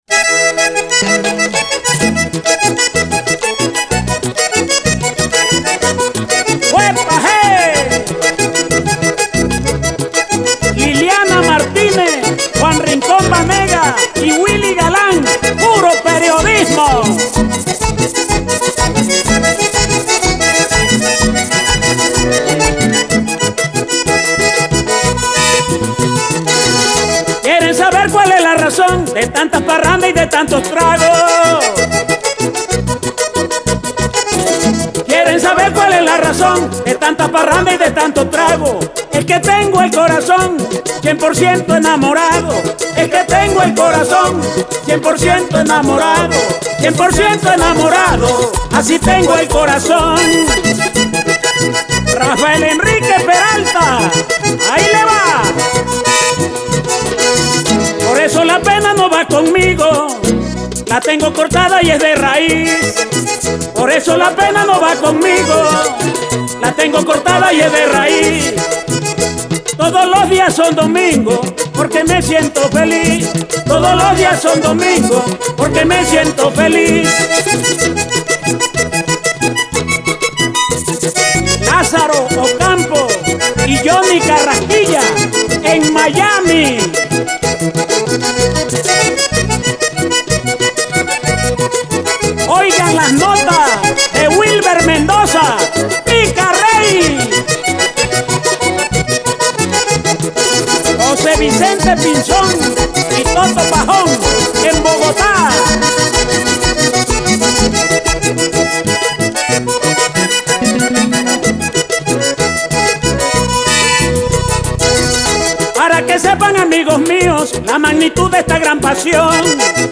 Aporte al folclor vallenato